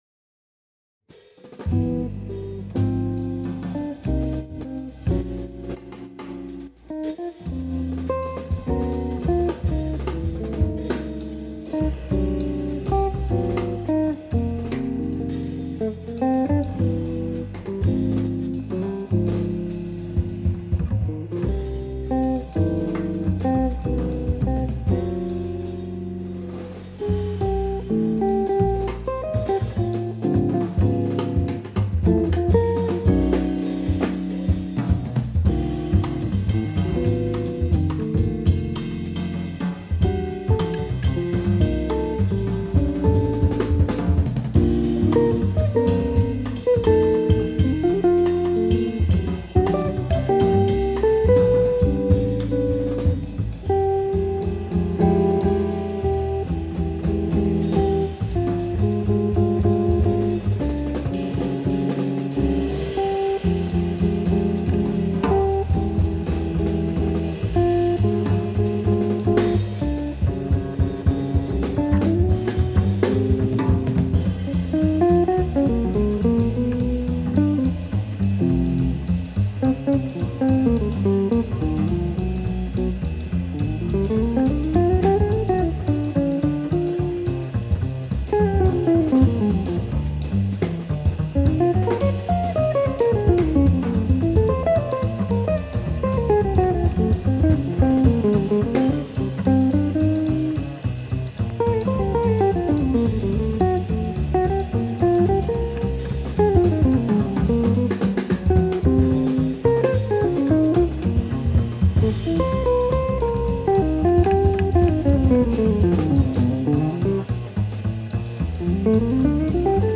Jazzland Le Jazz sans frontières Animée par deux explorateurs...